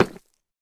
Minecraft Version Minecraft Version 25w18a Latest Release | Latest Snapshot 25w18a / assets / minecraft / sounds / block / deepslate_bricks / step4.ogg Compare With Compare With Latest Release | Latest Snapshot
step4.ogg